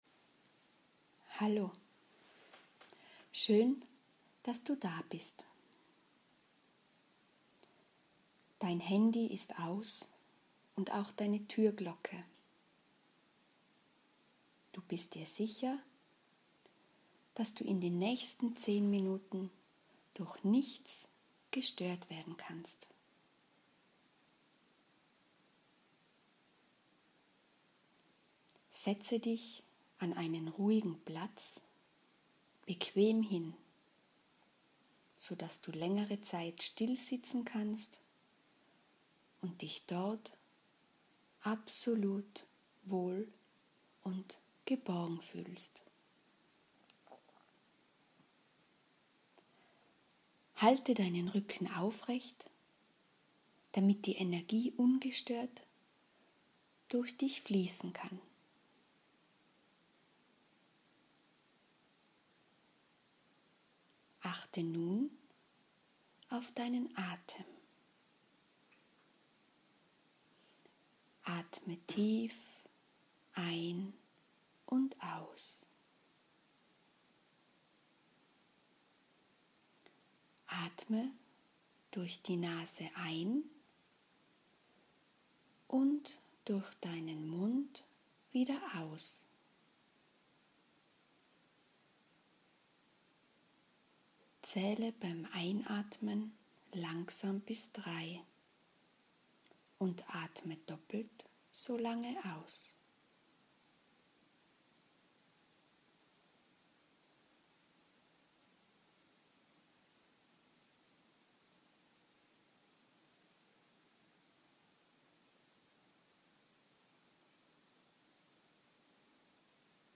Eine einfache Meditation zum Einstieg
Nun lade ich dich ein, einfach zuzuhören und dich führen zu lassen.
Die Aufnahmequalität ist vielleicht nicht perfekt – aber das ist nicht wichtig. Störe dich nicht an kleinen Nebengeräuschen. Folge einfach meiner Stimme und erlaube dir, zur Ruhe zu kommen.
Meditation.mp3